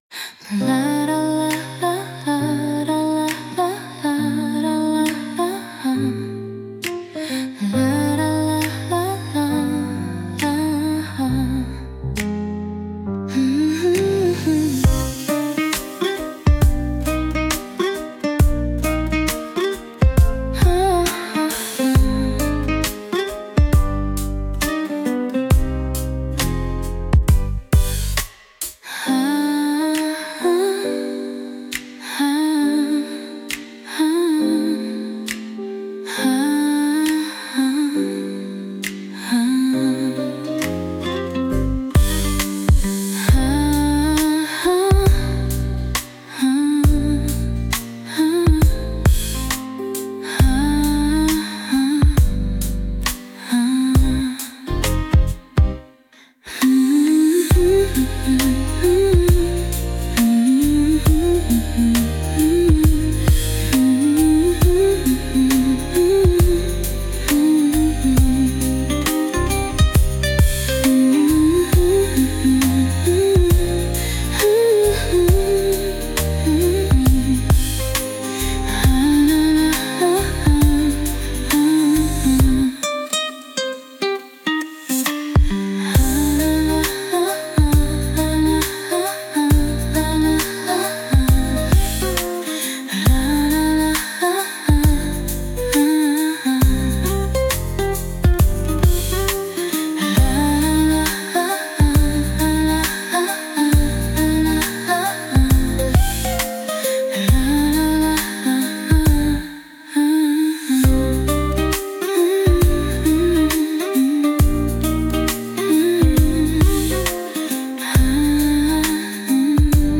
With Vocals / 歌あり
タイトル通り、静かで優しい女性の声が途切れることなく響き続ける、幻想的な一曲。
激しいビートがない分、指先や足先の美しさ、そして大人の女性らしい落ち着いた表現力が際立ちます。